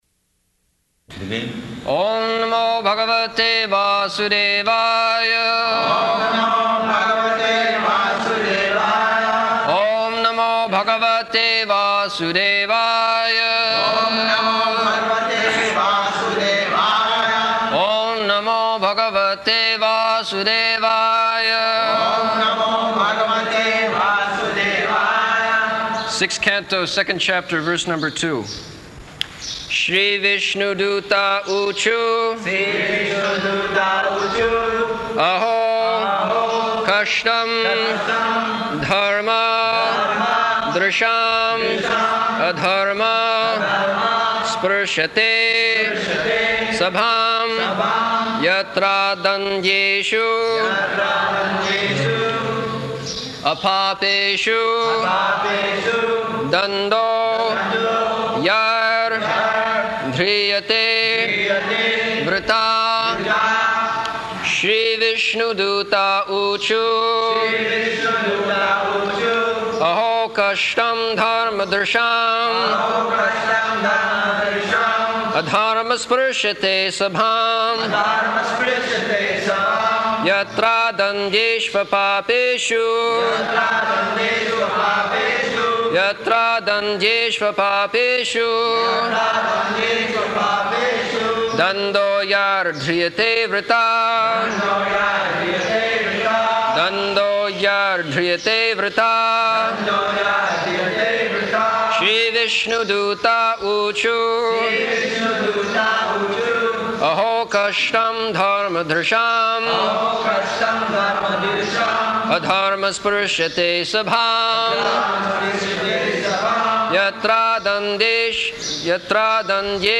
Śrīmad-Bhāgavatam 6.2.2 --:-- --:-- Type: Srimad-Bhagavatam Dated: September 6th 1975 Location: Vṛndāvana Audio file: 750906SB.VRN.mp3 Prabhupāda: Begin.
[devotees repeat] Sixth Canto, Second Chapter, verse number 2.